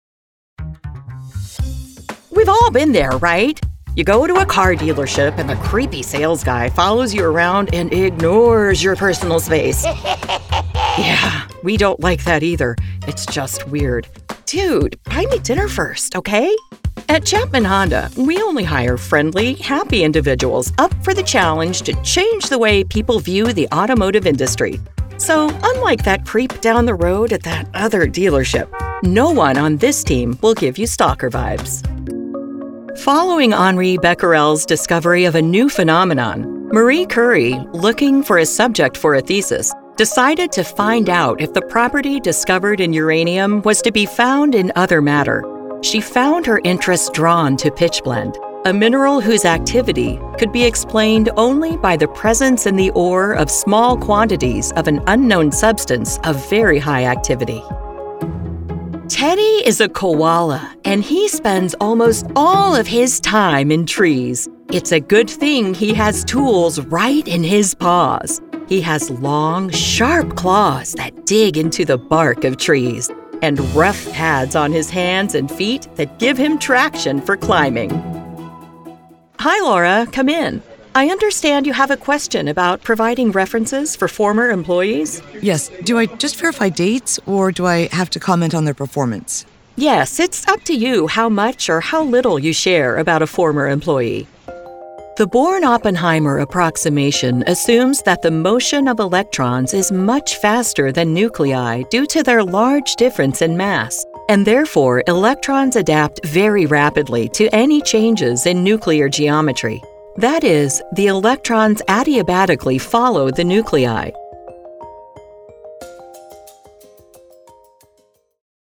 E-Learning
VO Demos